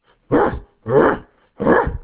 snarling moo sound.
GugglyUgly.wav